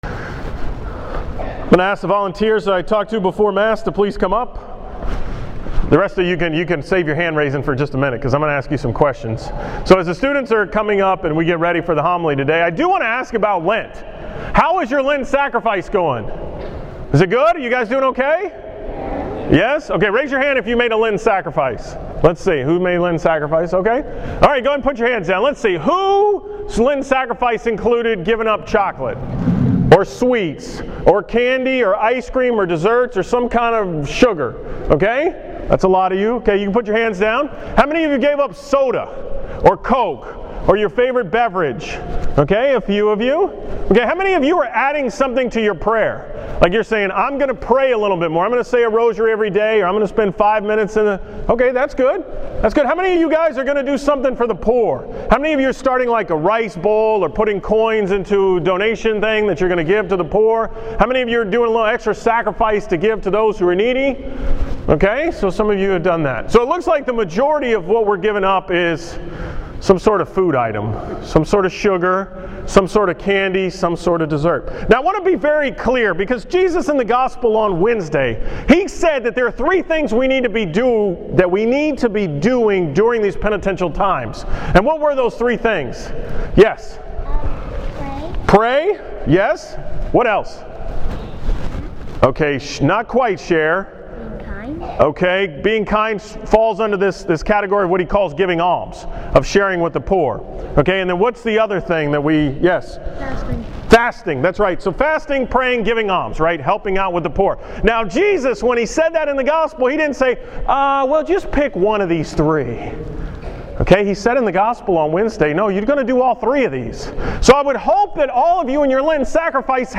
School Mass on Friday, March 7th.